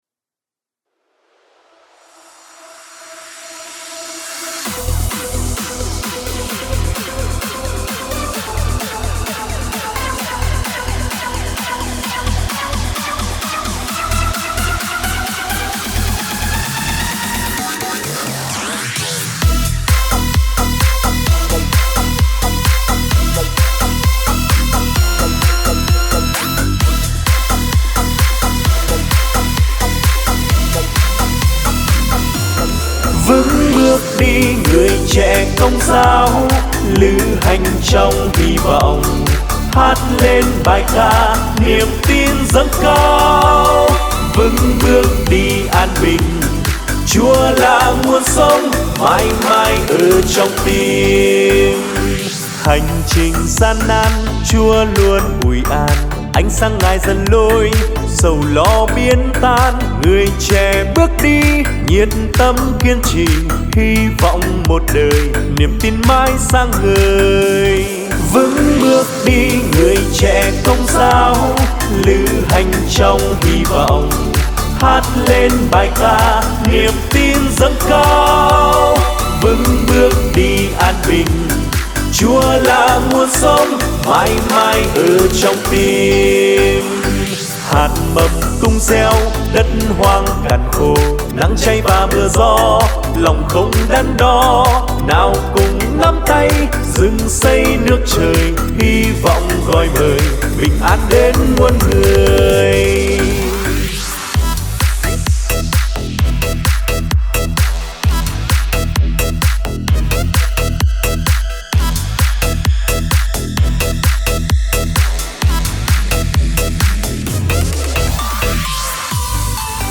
Nhạc Sinh Hoạt Giới Trẻ